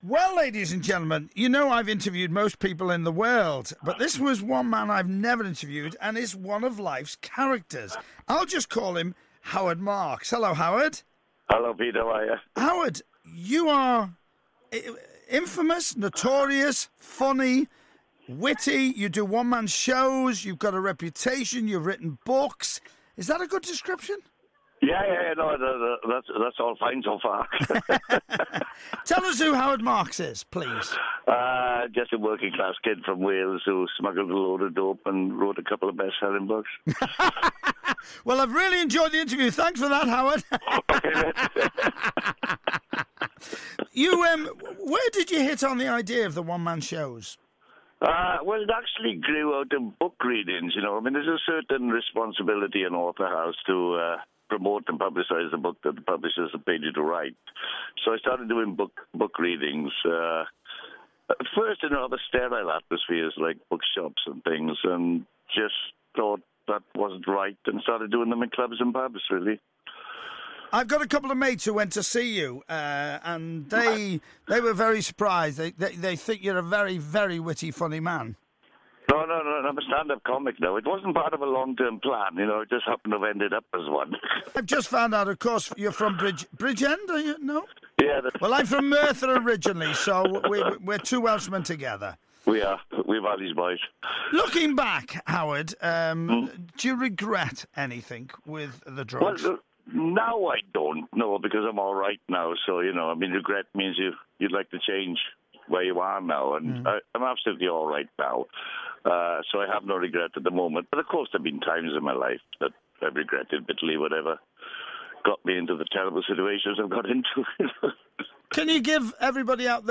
Listen again to this archive interview of Howard Marks chatting to Pete Price